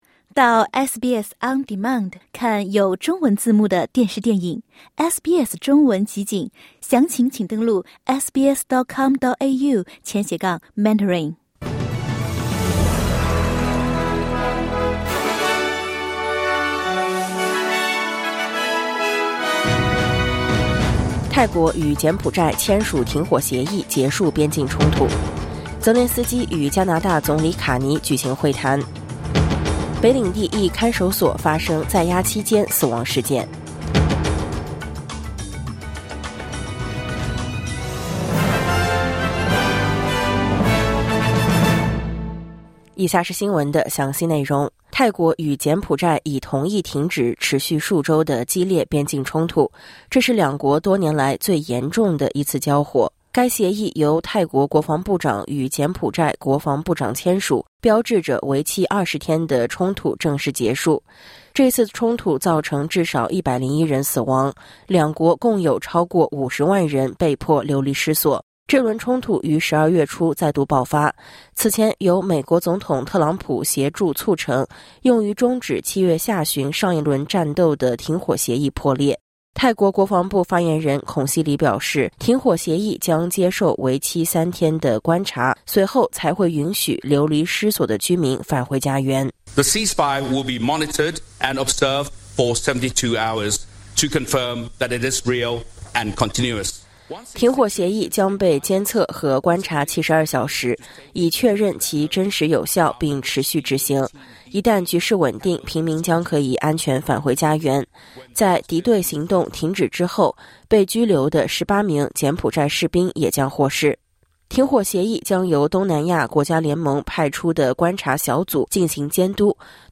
【SBS早新闻】泰国柬埔寨达成停火协议